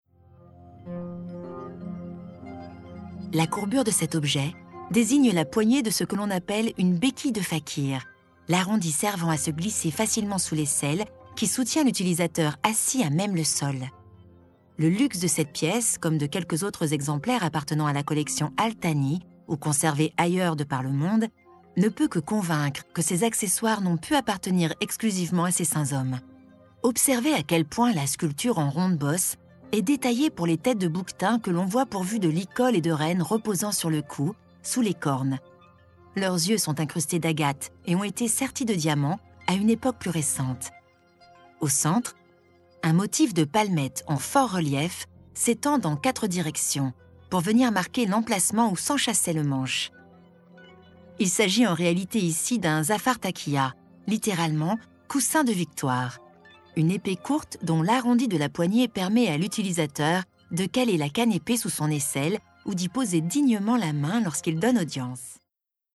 Audioguide au Musée d’Orsay
passionnée // historique // douce
Audioguide-Orsay-passionnee-historique-douce.mp3